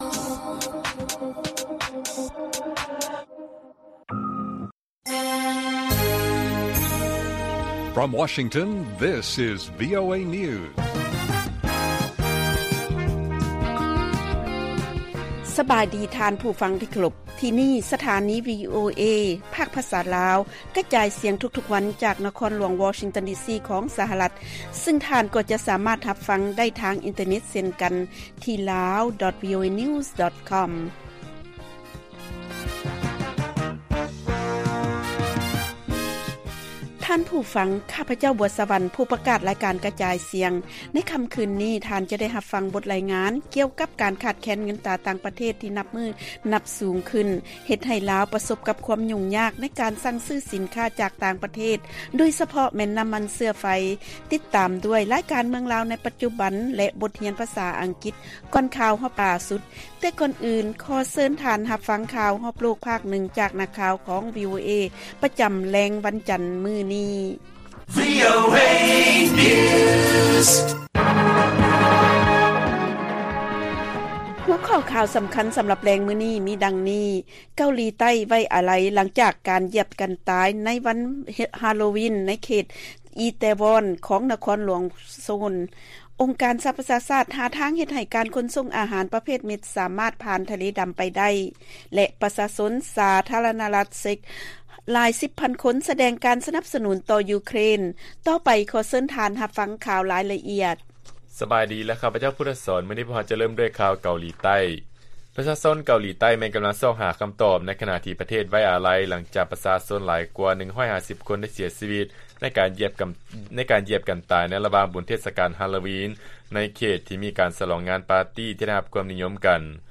ລາຍການກະຈາຍສຽງຂອງວີໂອເອ ລາວ: ເກົາຫຼີໃຕ້ ໄວ້ອາໄລ ຫຼັງຈາກການຢຽບກັນຕາຍວັນ ຮາໂລວີນ ໃນເຂດ ອີແຕວອນ ຂອງນະຄອນຫຼວງ ໂຊລ